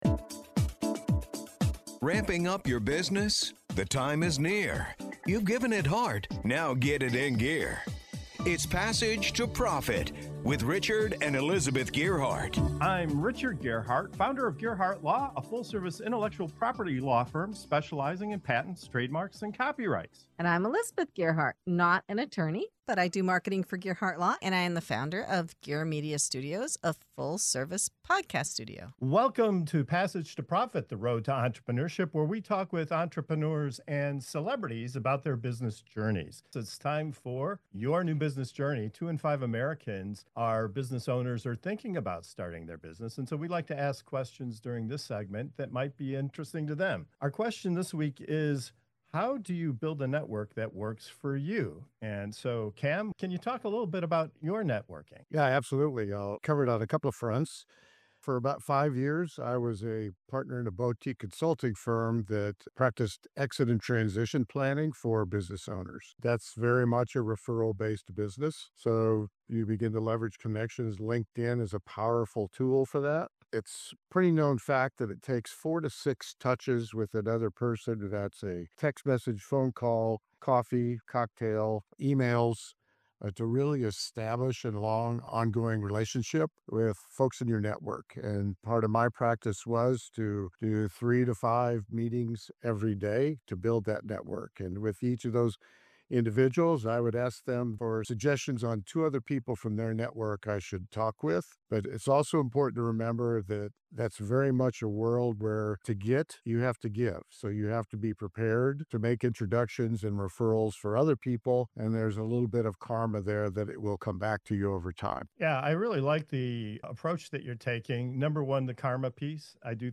In this inspiring segment of "Your New Business Journey" on Passage to Profit Show, our hosts and guests share personal stories and practical tips on how to grow meaningful business connections—with a strong focus on giving before getting. From introverts leveraging service to storytellers turning passion into referrals, this conversation shows how authentic relationships, consistency, and a little karma can turn casual contacts into career-changing allies.